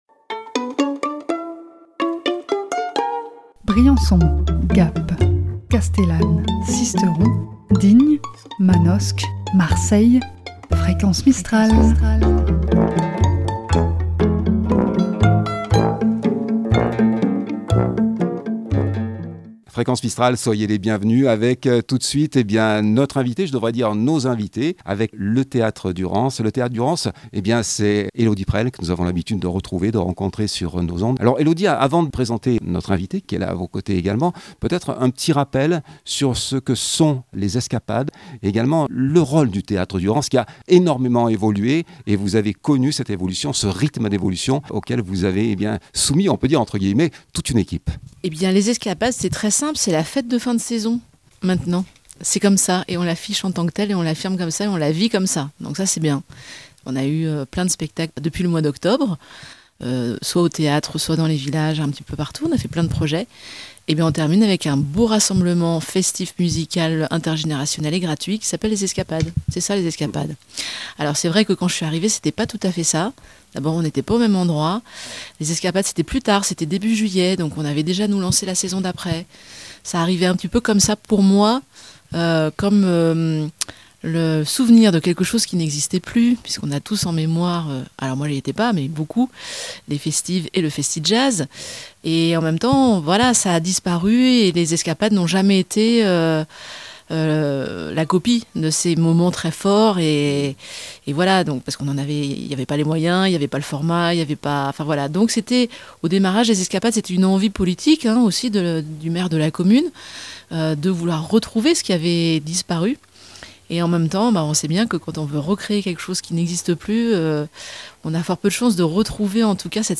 Emission Theatre Durance Les Escapades.mp3 (74.31 Mo)